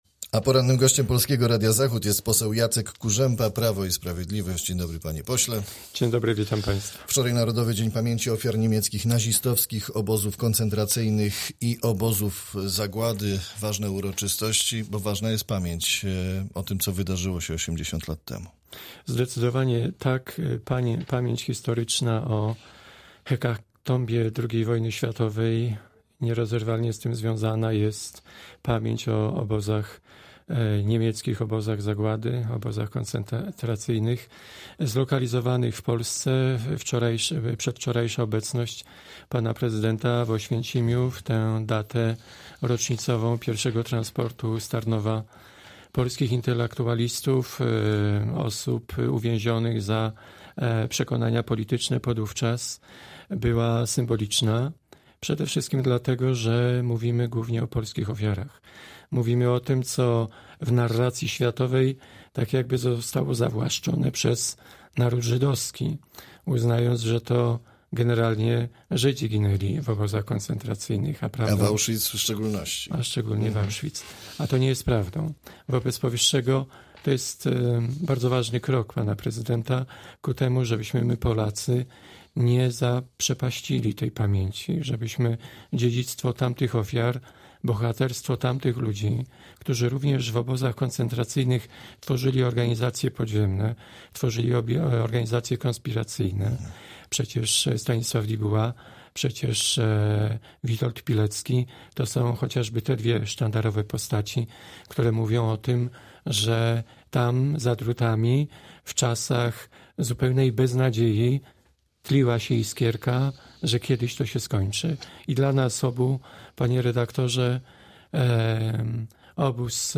Z posłem PiS rozmawia